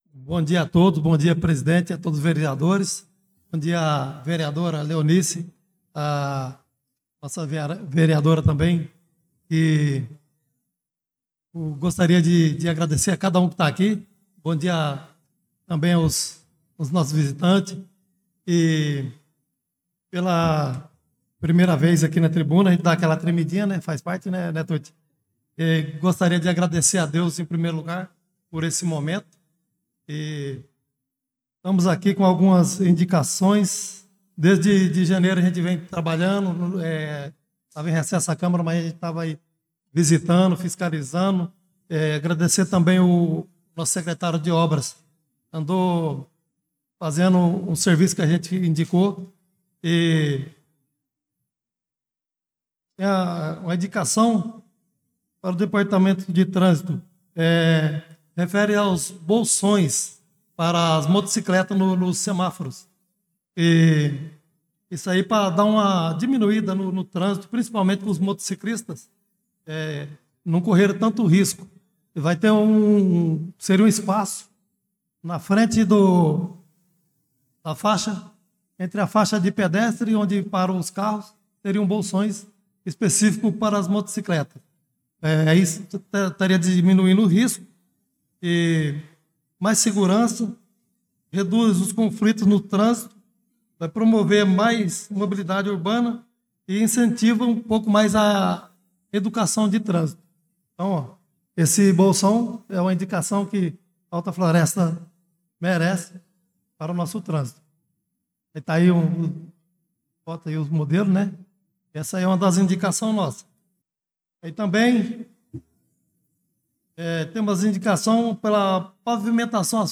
Pronunciamento do Vereador Chicão Motocross
pronunciamento-do-vereador-chicao-motocross